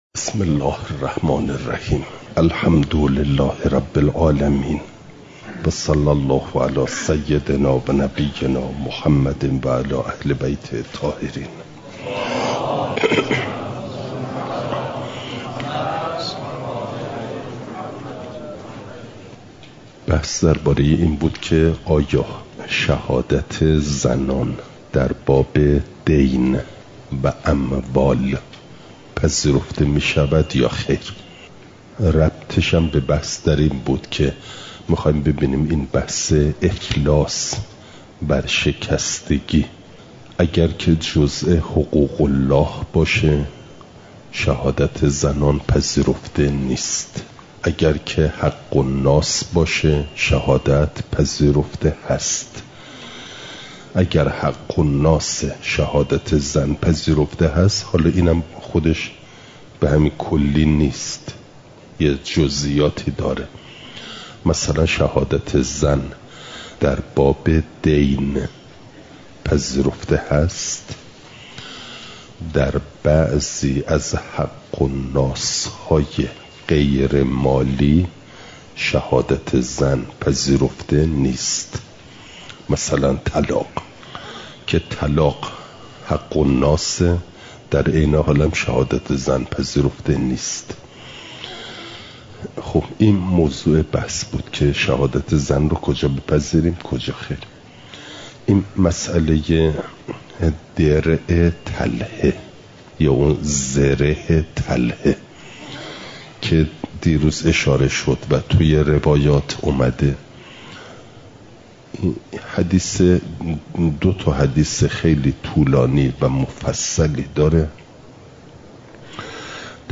مسائل مستحدثه قضا (جلسه۲۵) « دروس استاد